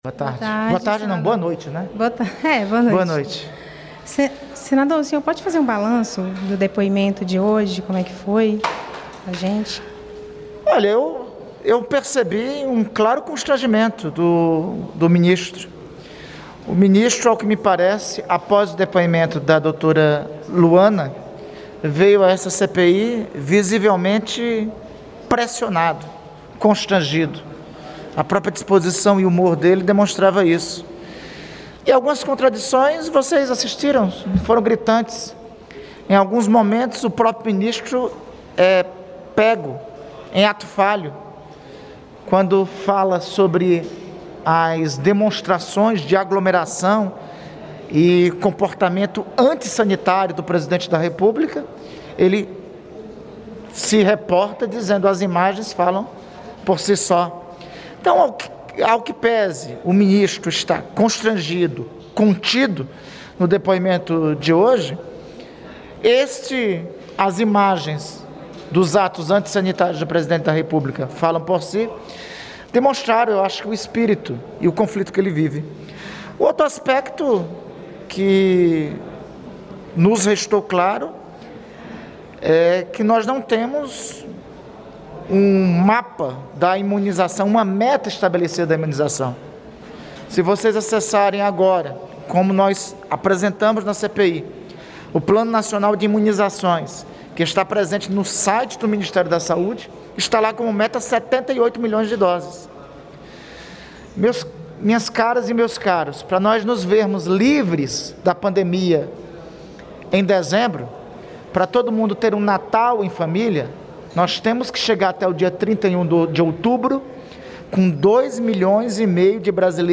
Entrevista coletiva com o vice-presidente da CPI, Randolfe Rodrigues
O senador Randolfe Rodrigues (Rede-AP), vice-presidente da CPI da Pandemia, concedeu entrevista coletiva nesta terça-feira (8), logo após o segundo depoimento do ministro da Saúde, Marcelo Queiroga.